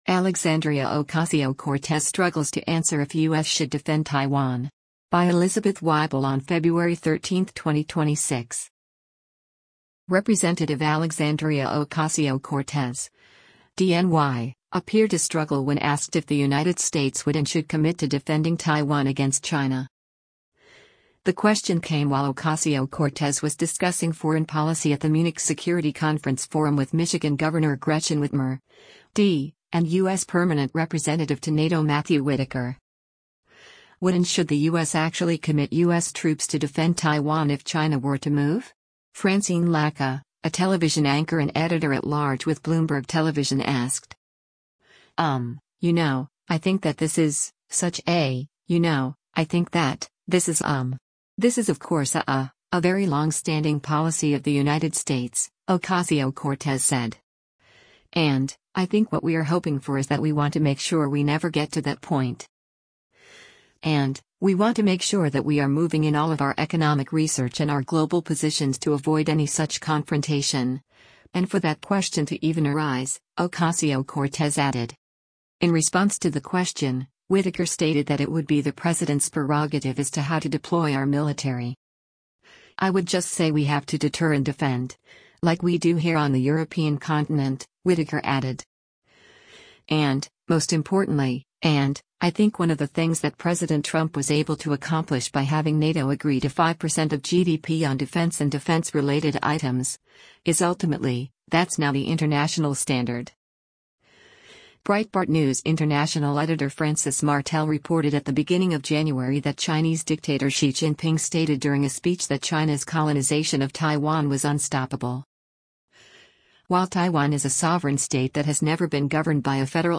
Rep. Alexandria Ocasio-Cortez (D-NY) appeared to struggle when asked if the United States “would and should” commit to defending Taiwan against China.
The question came while Ocasio-Cortez was discussing foreign policy at the Munich Security Conference Forum with Michigan Gov. Gretchen Whitmer (D), and U.S. Permanent Representative to NATO Matthew Whitaker.